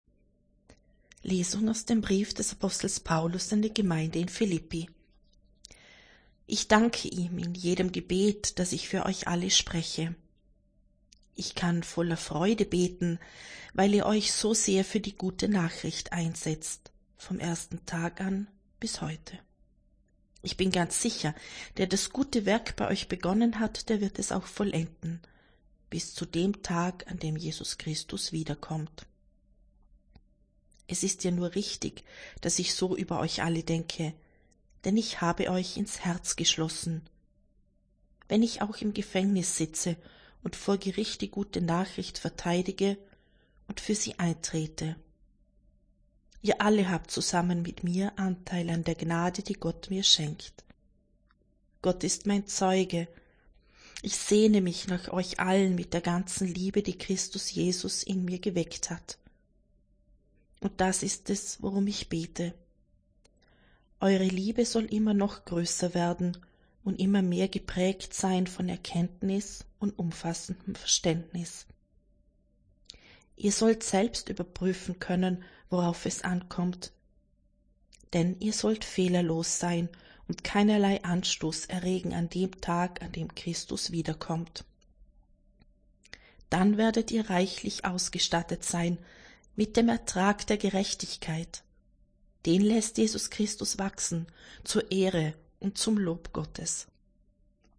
Wenn Sie den Text der 2. Lesung aus dem Brief des Apostels Paulus an die Gemeinde in Philíppi anhören möchten: